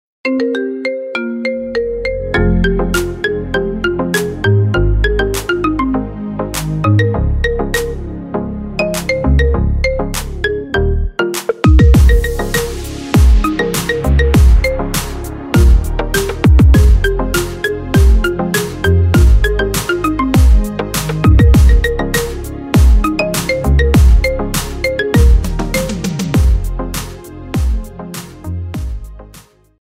Categoría Marimba Remix